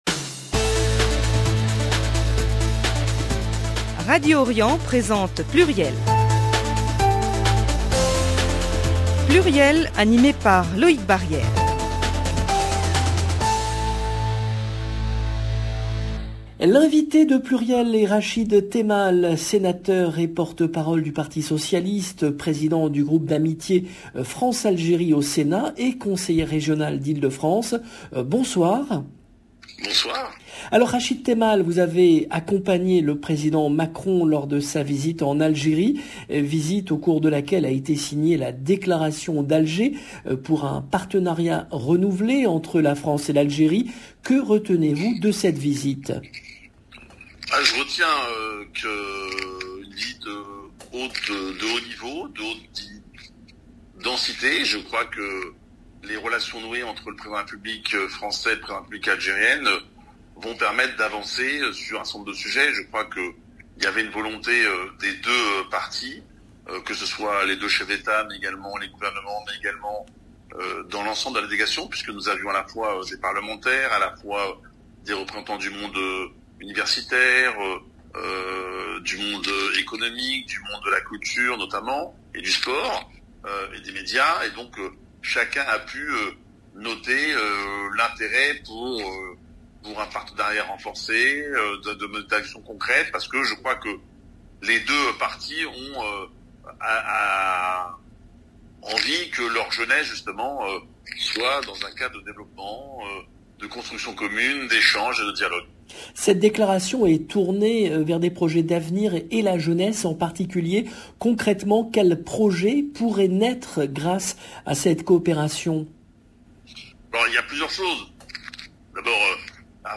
L’invité de PLURIEL est Rachid Temal, sénateur et porte-parole du parti socialiste, président du groupe d’amitié France Algérie au Sénat et conseiller régional d’Ile-de-France Lors de cette émission, Rachid Temal revient notamment sur la Déclaration d'Alger, signée à l'occasion du voyage effectué par Emmanuel Macron en Algérie.